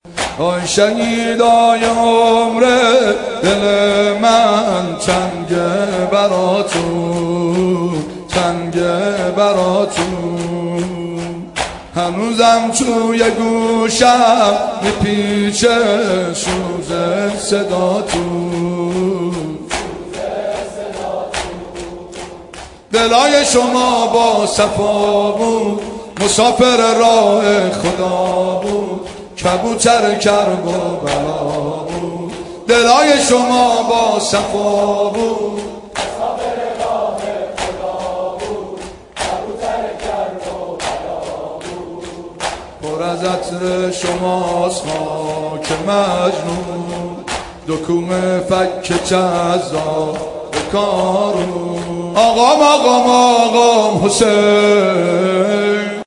نوای دلنشین شهدایی